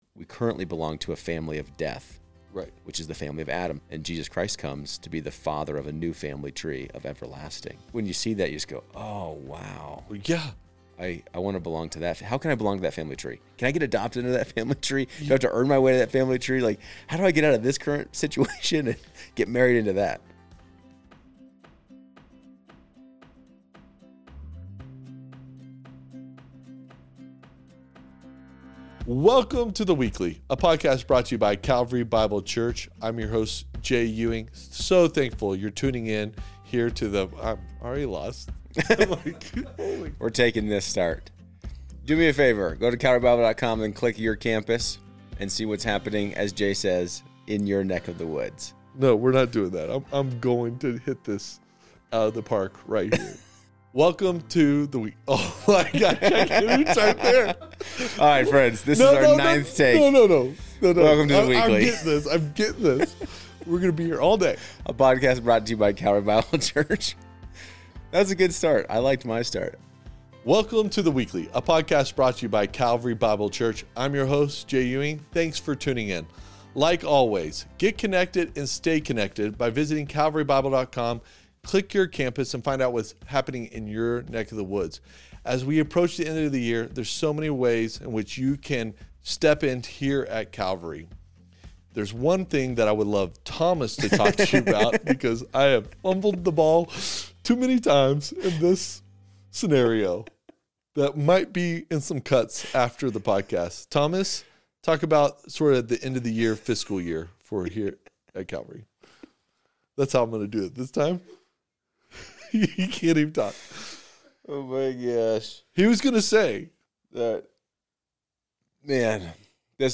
They discuss how these titles reveal who Jesus truly is, why His coming matters, and how Advent calls us to live with expectation and readiness for His return. This conversation invites listeners to slow down during a busy season, anchor their hope in Christ, and rediscover the depth and meaning of Advent.